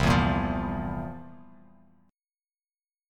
C11 Chord
Listen to C11 strummed